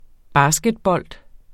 Udtale [ ˈbɑːsgədˌbʌlˀd ]